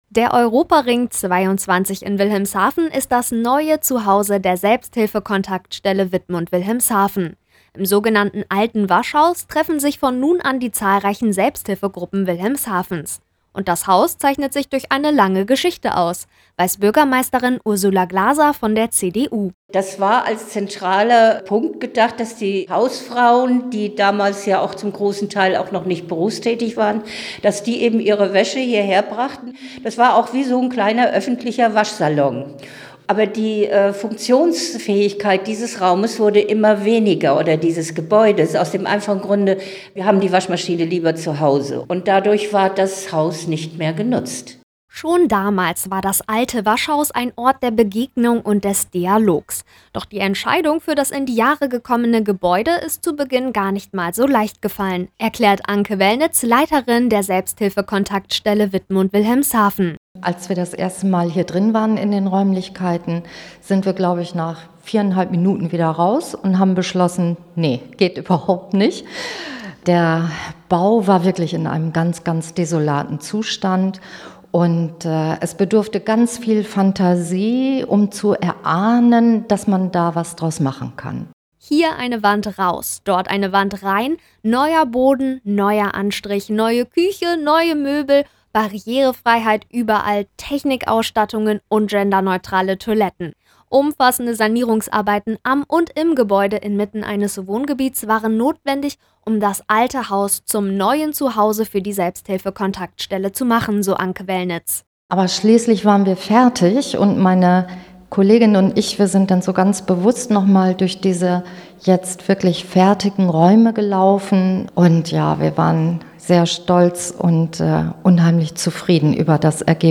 Beitrag Radio Jade